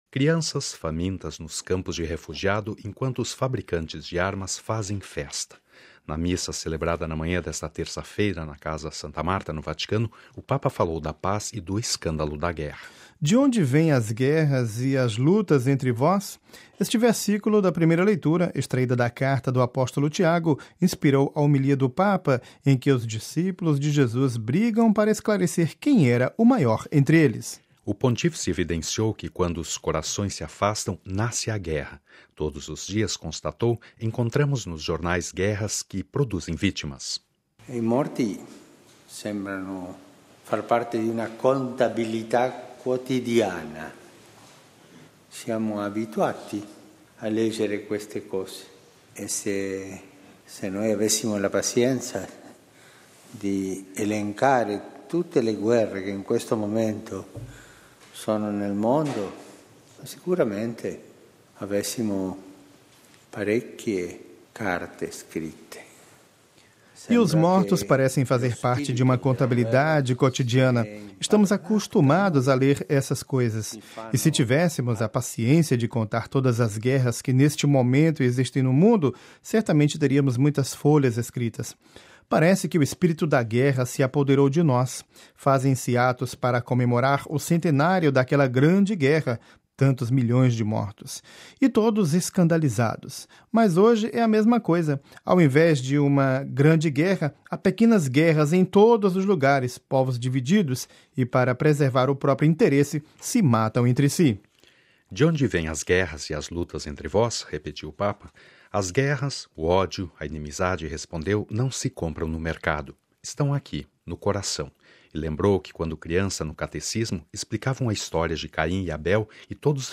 MP3 Cidade do Vaticano (RV) – Crianças famintas nos campos de refugiados, enquanto os fabricantes de armas fazem festa: na missa celebrada esta manhã na Casa Santa Marta, o Papa falou da paz e do escândalo da guerra.